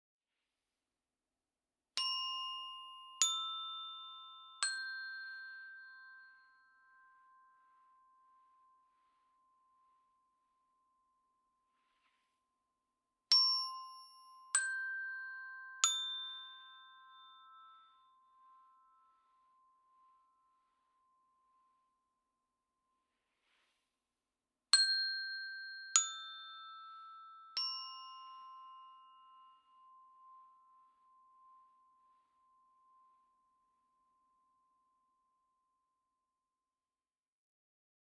Meinl Sonic Energy Three Tone Energy Chime - Major Triad/440 Hz (ECTT3)